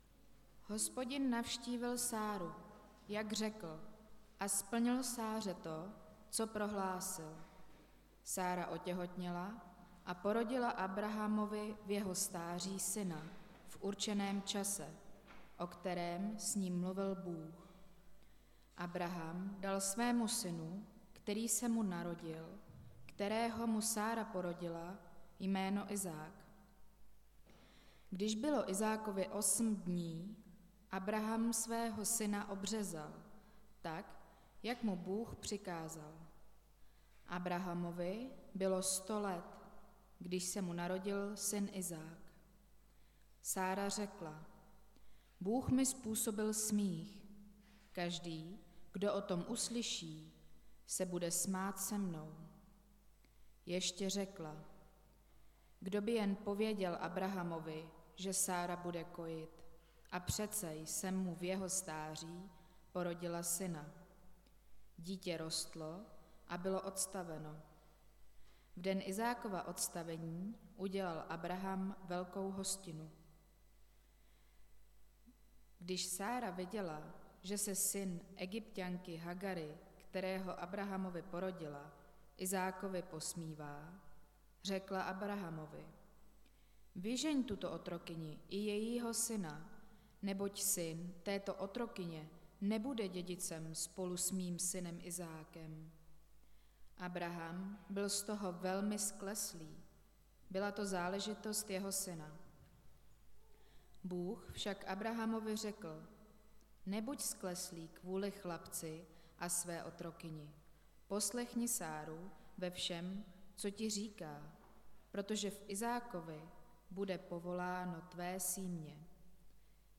Nedělní kázání – 1.8.2023 Narození Izáka